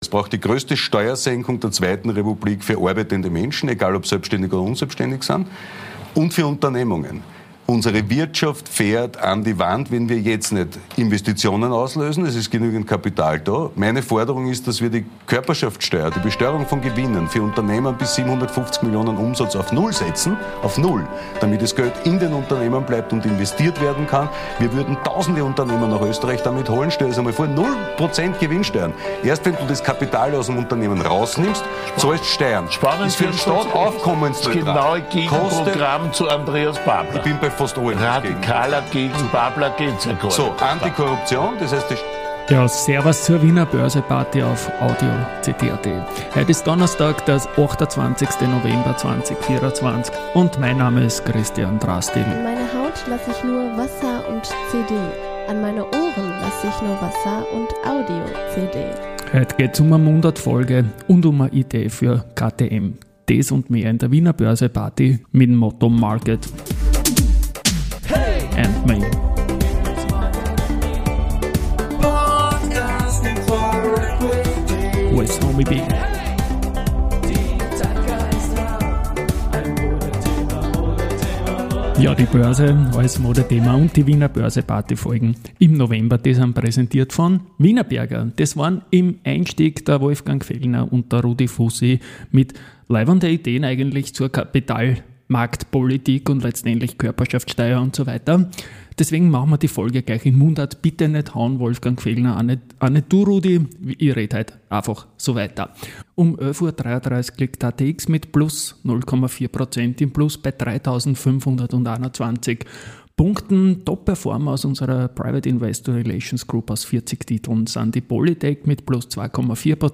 Mundart-Folge